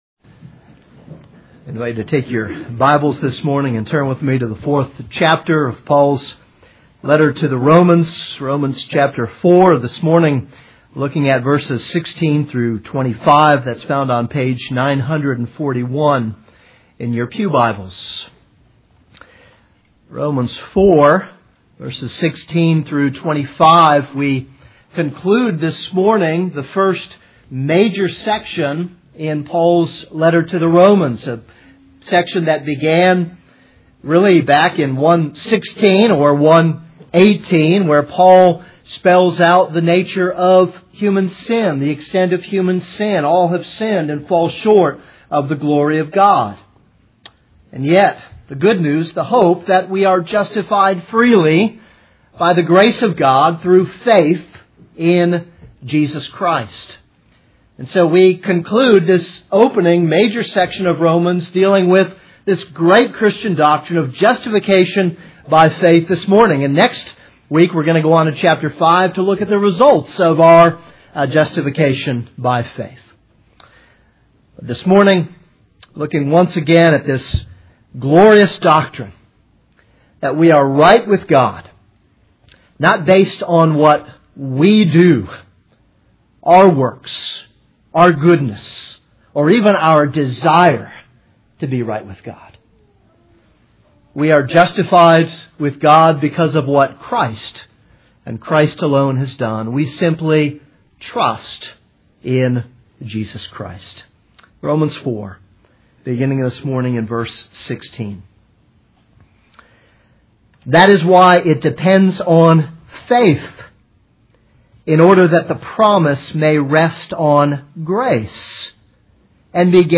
This is a sermon on Romans 4:16-25.